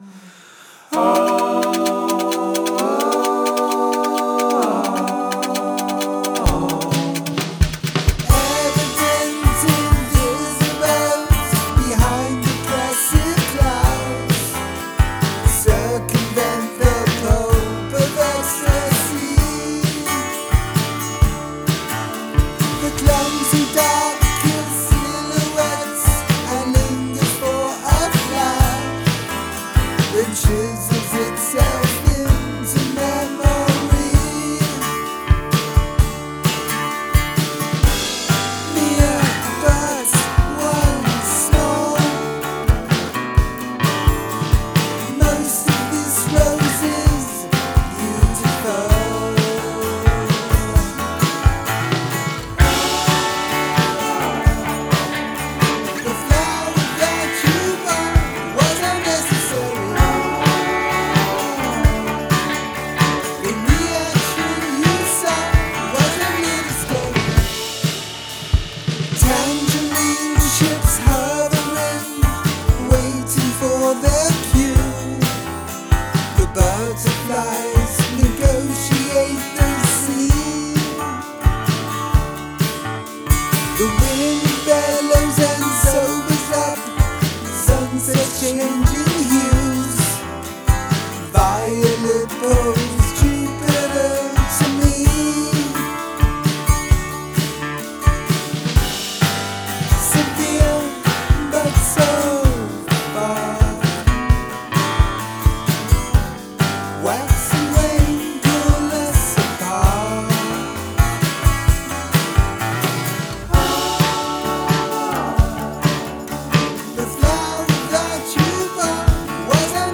Psychedelic